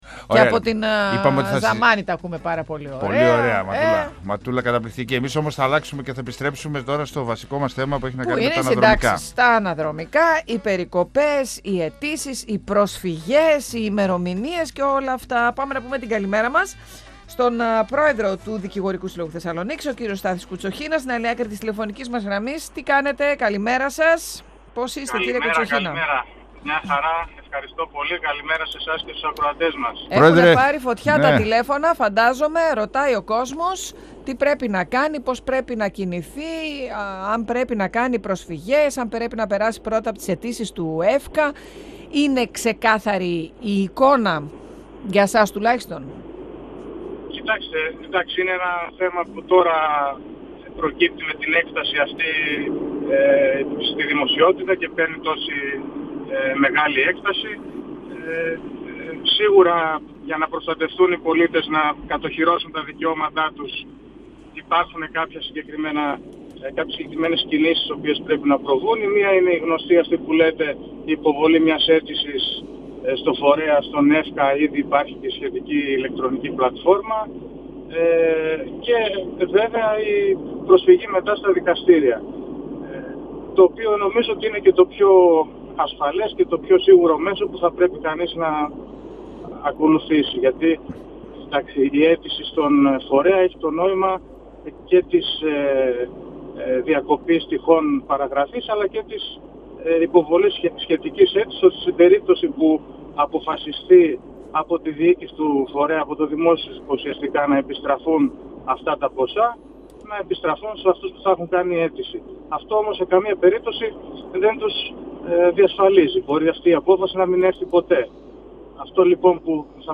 Ωστόσο τόνισε ότι θα ήταν ορθό να υπάρξει κεντρική απόφαση για το θέμα των περικοπών. 102FM Συνεντεύξεις ΕΡΤ3